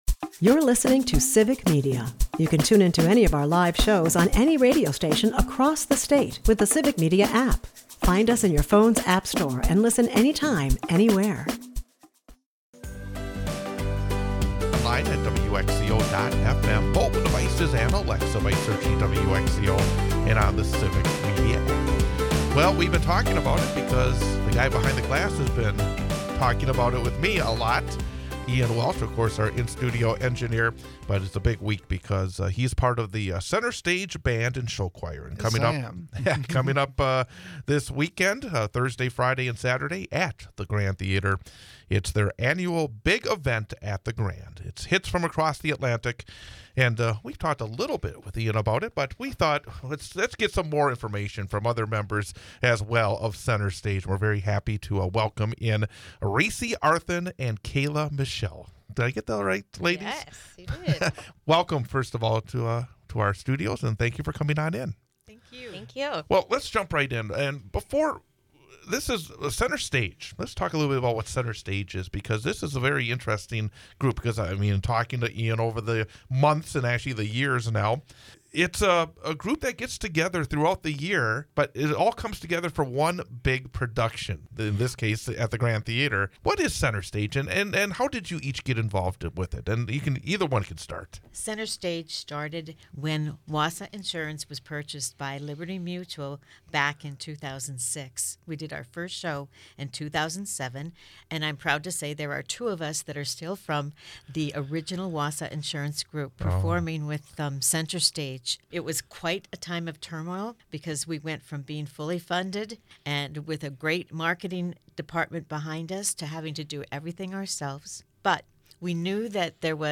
joins us for an interview on an upcoming Adult Spelling Bee. Tom Tiffany announces his candidacy for the Republican nomination for Governor.